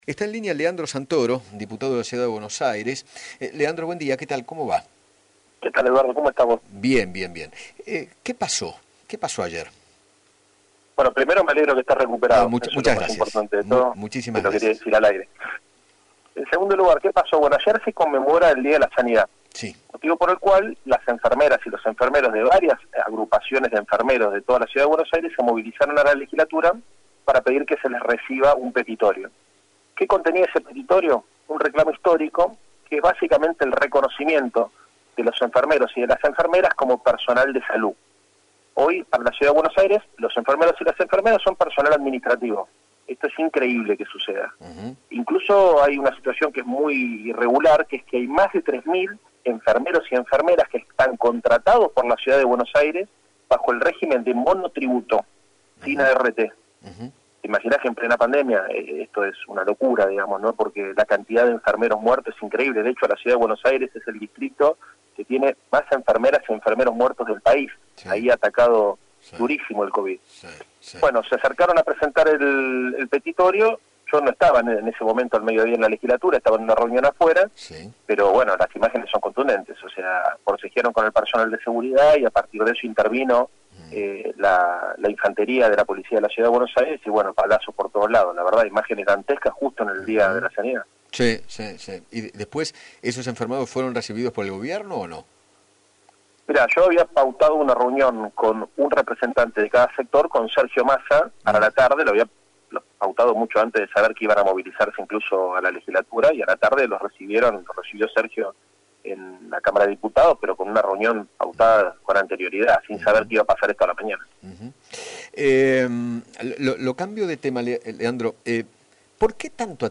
Leandro Santoro, diputado de la Ciudad de Buenos Aires, dialogó con Eduardo Feinmann acerca de la relación con el jefe de Gobierno de la Ciudad y aseguró que tiene “buen diálogo con casi todos los ministros de CABA”. Además, habló de lo sucedido ayer con los enfermeros frente a la Legislatura porteña y de la disputa por la vuelta a clases.